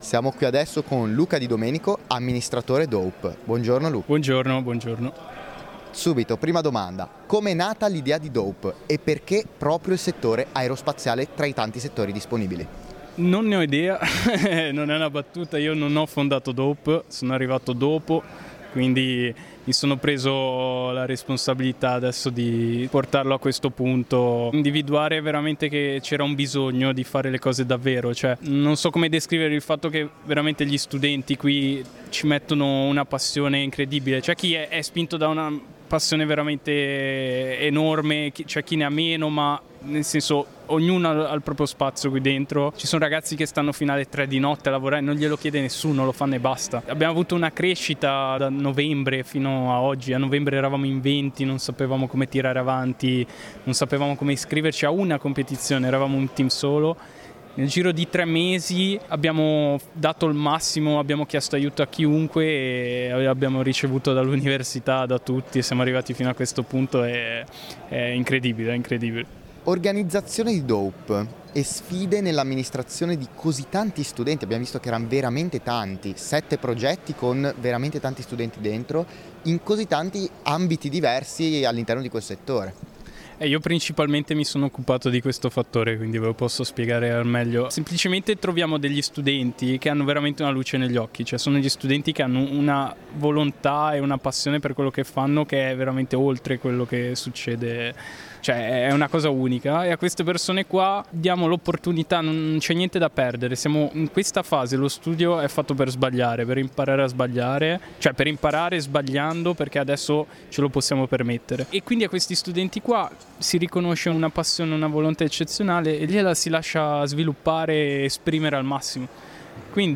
Intervista di